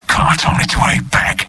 Robot-filtered lines from MvM. This is an audio clip from the game Team Fortress 2 .
{{AudioTF2}} Category:Sniper Robot audio responses You cannot overwrite this file.
Sniper_mvm_cartgoingbackdefensesoft03.mp3